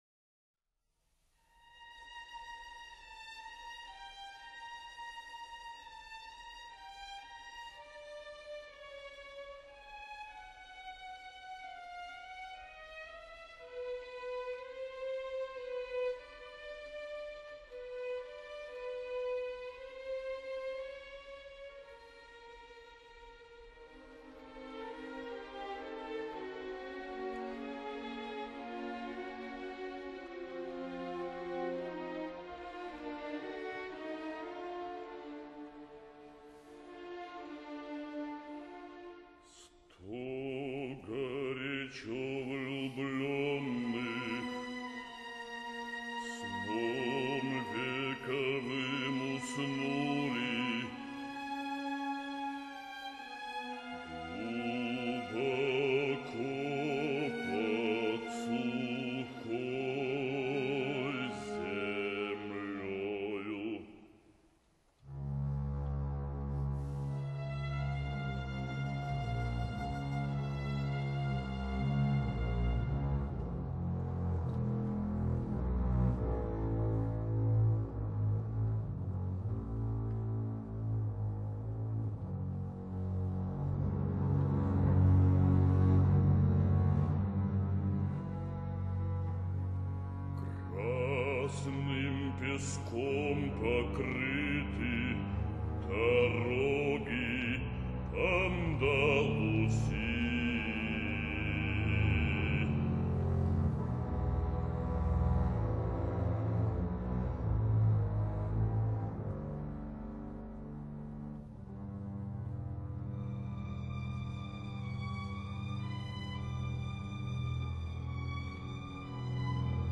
分辑：CD1-CD11 交响曲全集